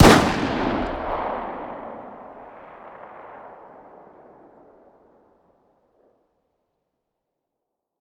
fire-dist-357sig-pistol-ext-03.ogg